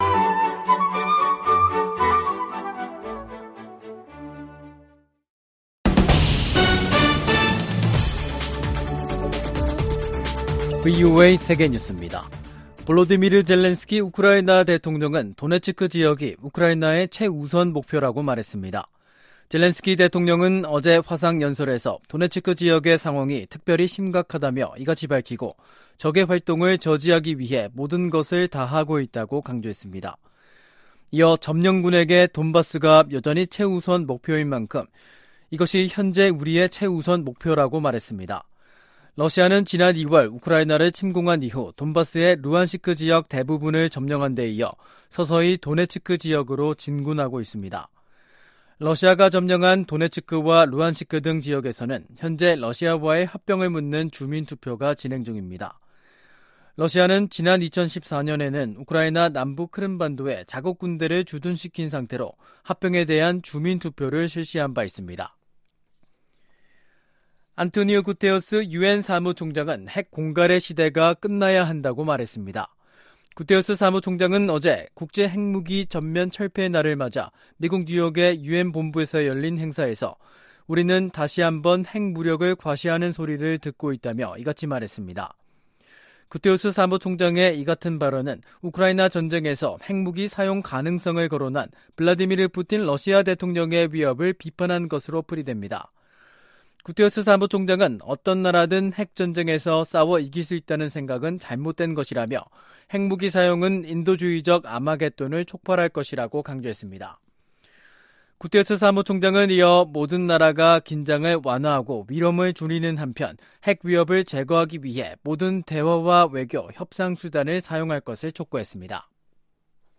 VOA 한국어 간판 뉴스 프로그램 '뉴스 투데이', 2022년 9월 27일 3부 방송입니다. 미 국무부가 북한 정권의 어떤 도발도 한국과 일본에 대한 확고한 방어 의지를 꺾지 못할 것이라고 강조했습니다. 카멀라 해리스 미 부통령과 기시다 후미오 일본 총리가 회담에서 북한 정권의 탄도미사일 발사를 규탄했습니다. 북한이 핵 개발에 쓴 비용이 최대 16억 달러에 달한다는 분석 결과가 나왔습니다.